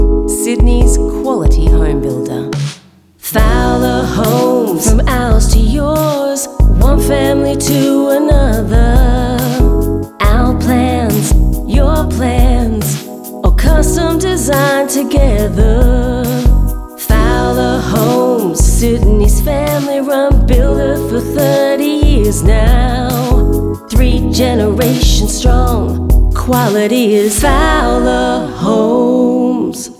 brand voice and singer
30-second radio spot